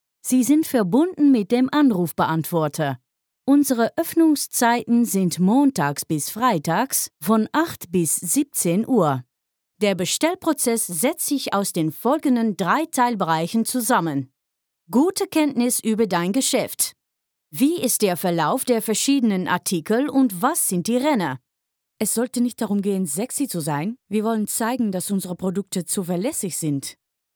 Commercial Demo
My voice sounds young, fresh and enthusiastic, but reliable.
Mic: Sennheiser MKH416